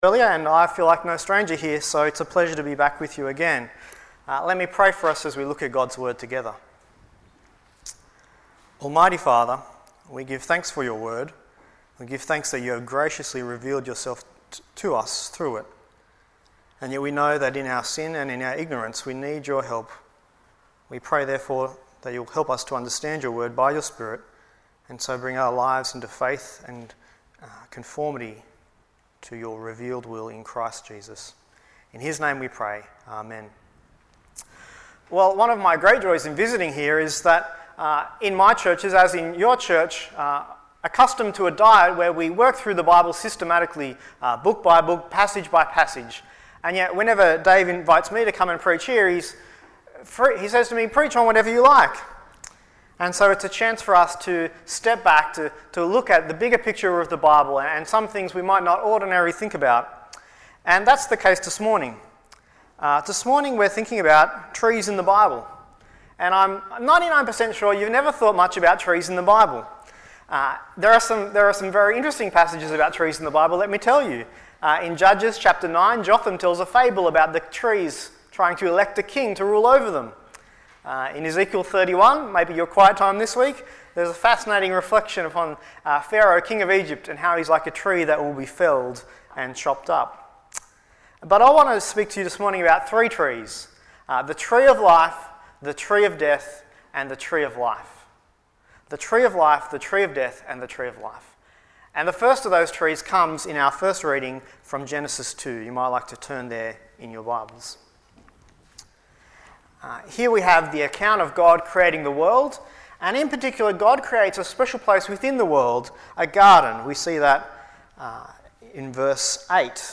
Guest Preacher Passage: Genesis 2:4-17, Revelation 21:9-27 Service Type: Sunday Morning